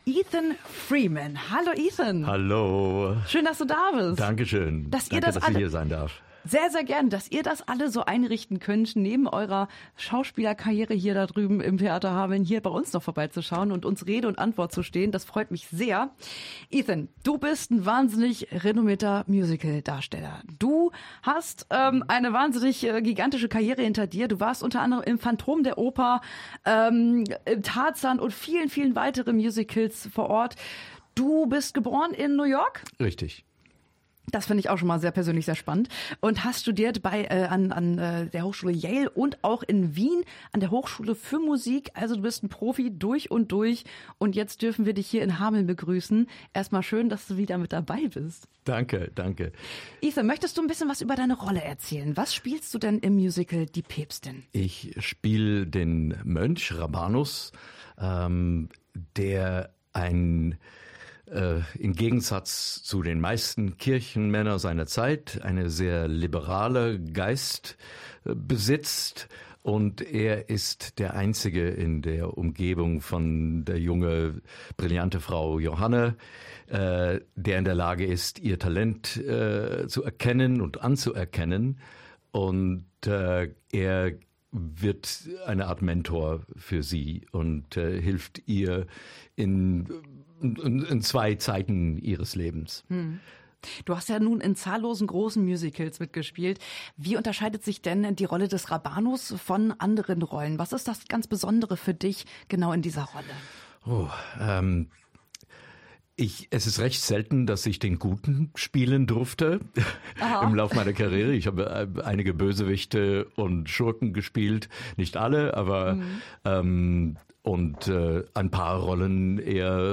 Live-Interview